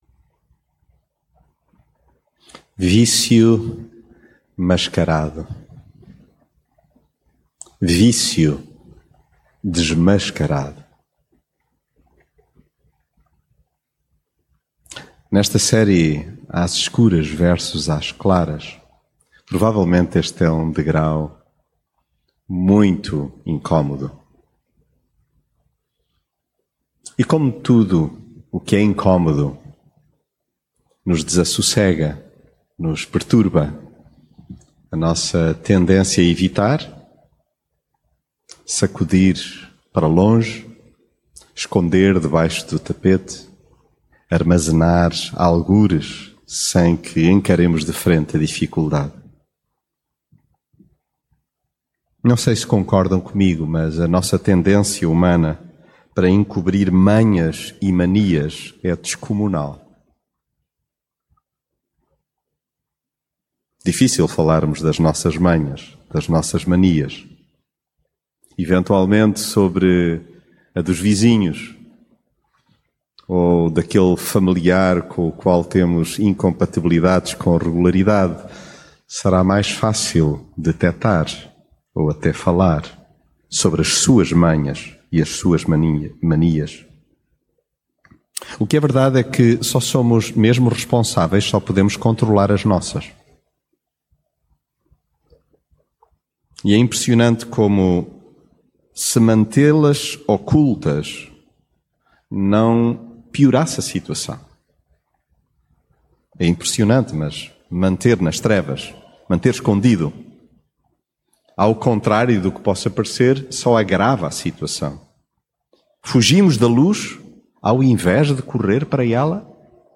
às escuras versus às claras mensagem bíblica A tendência humana para encobrir manhas e manias é descomunal.